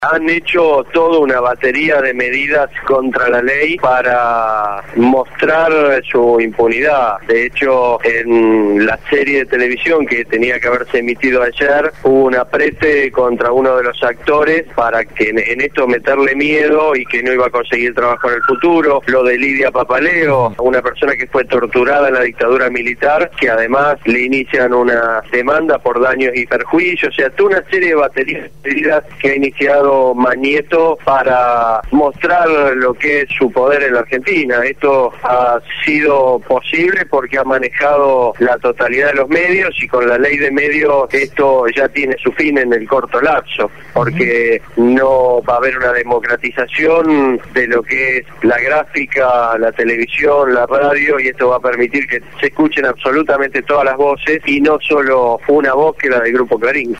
Daniel Reposo, Titular de la SIGEN «Sindicatura General de la Nacion»